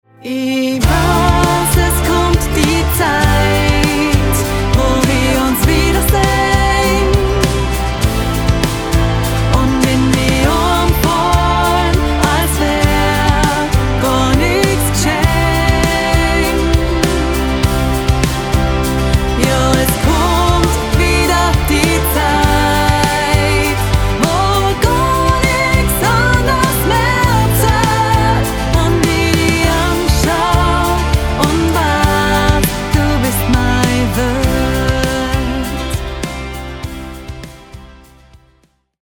Genre: Schlager